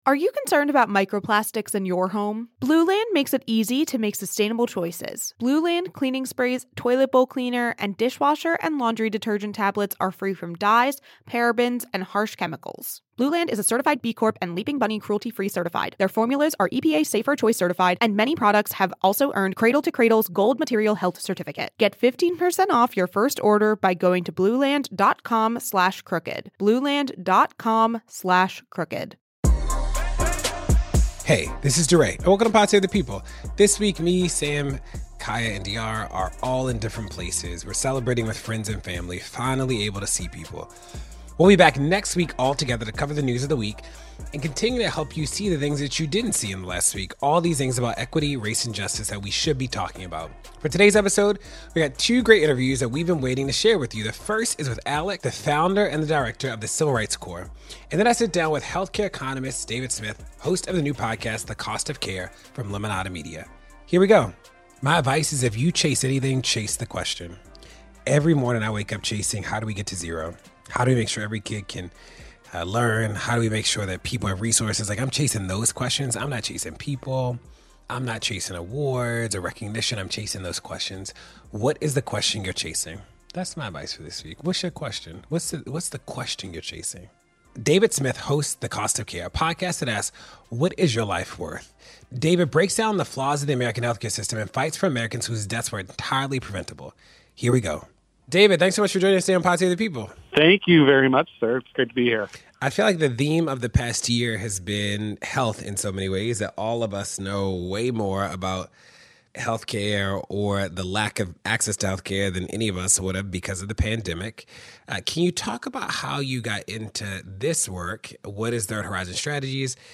DeRay interviews